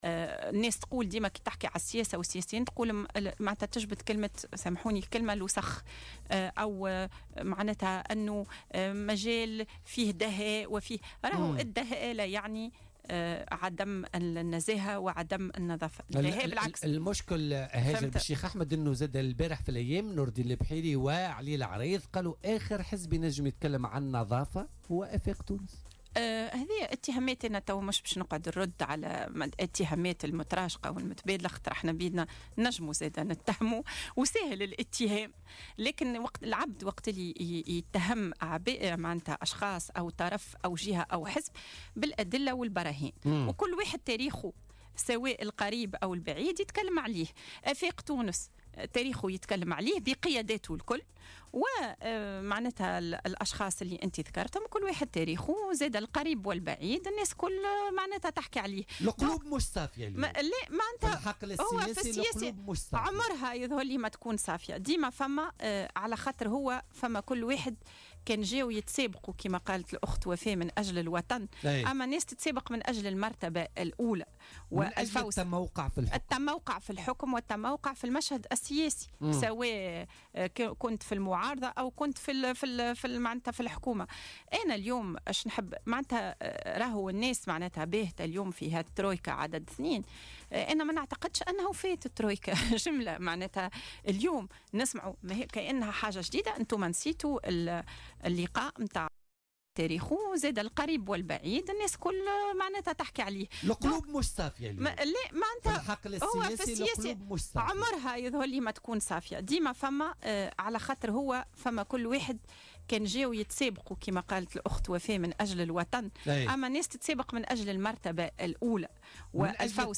أكدت النائب عن افاق تونس هاجر بالشيخ أحمد ضيفة برنامج "بوليتيكا" اليوم الخميس، 16 نوفمبر 2017 أن الترويكا لم تنته أصلا لنتحدث اليوم عن ترويكا جديدة.